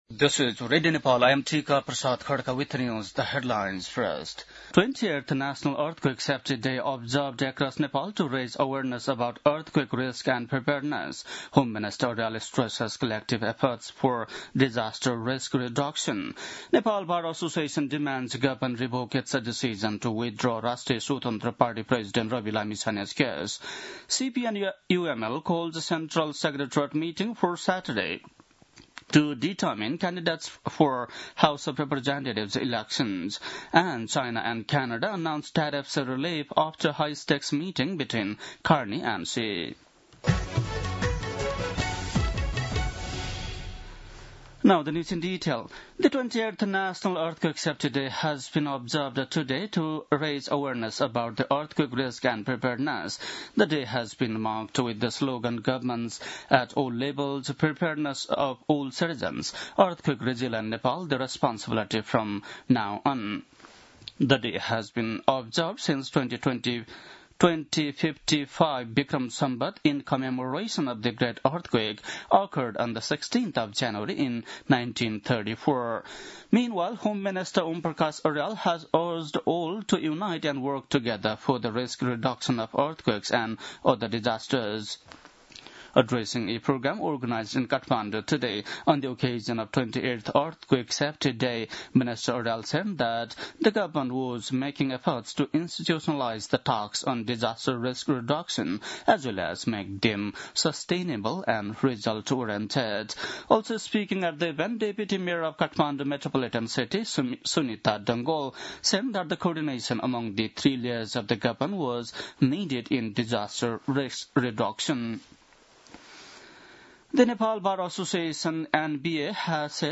बेलुकी ८ बजेको अङ्ग्रेजी समाचार : २ माघ , २०८२